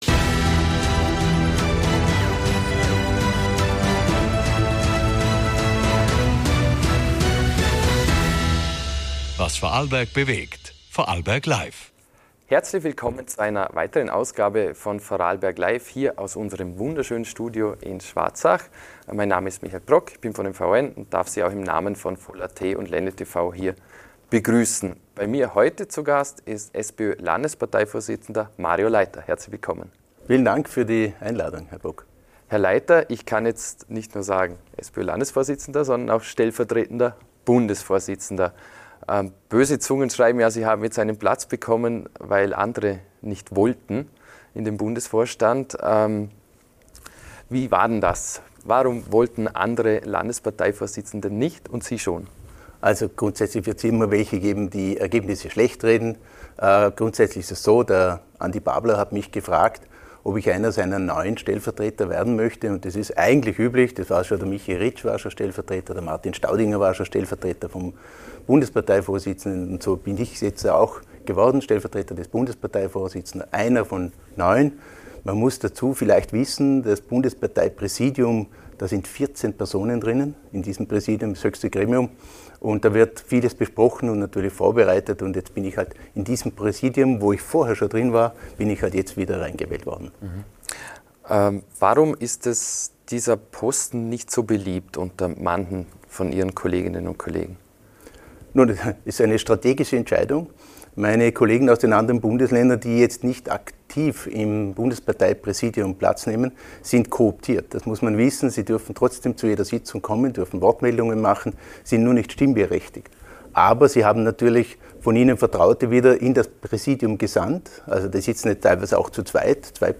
Im Gespräch spricht er über Loyalität zu Andi Babler, interne Debatten und die Rolle Vorarlbergs in der Bundespartei. Im Fokus stehen jedoch die Themen im Land: leistbarer Wohnraum, Sozialkürzungen, die Rolle von Christoph Bitschi und die Forderung nach mehr Transparenz in der Landesregierung. Auch zur Wehrpflicht und möglichen Volksbefragungen nimmt Leiter Stellung.